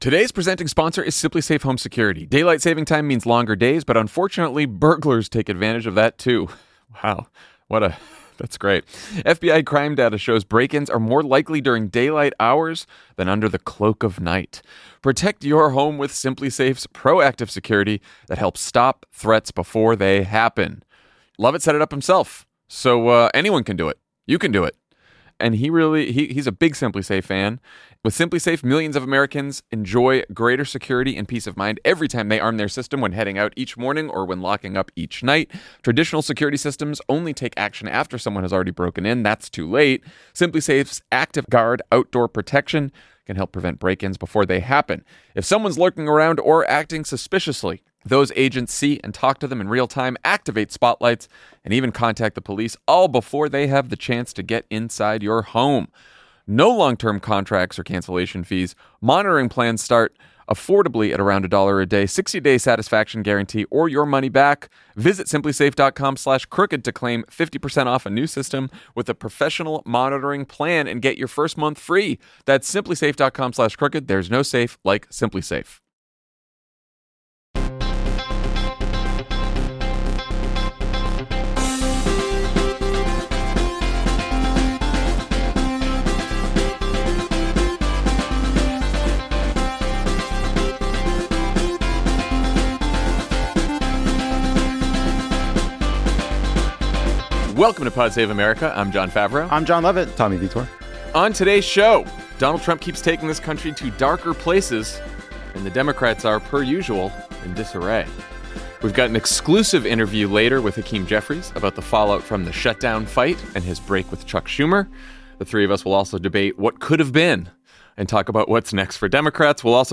Jon, Lovett, and Tommy break down the administration’s defiance of the courts and debate both sides of the shutdown fight. Then, Dan talks to House Minority Leader Hakeem Jeffries about his break with Schumer and how Democrats can unite against the Trump administration.